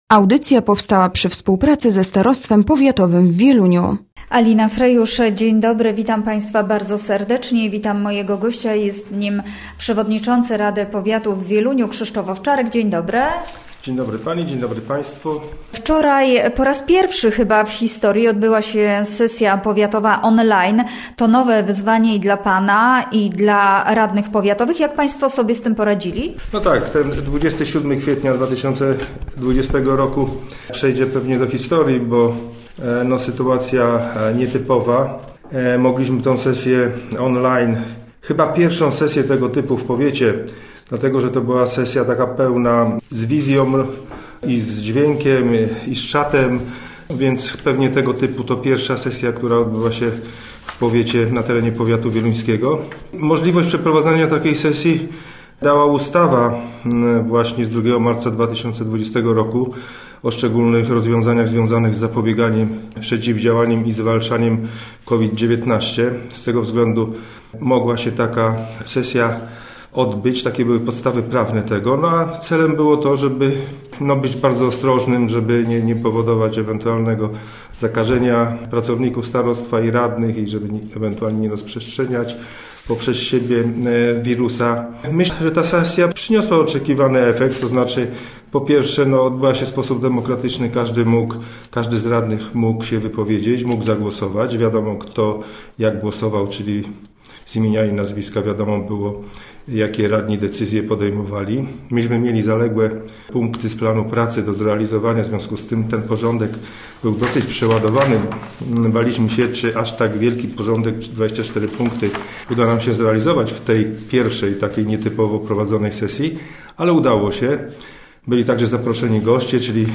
Gościem Radia ZW był Krzysztof Owczarek, przewodniczący Rady Powiatu w Wieluniu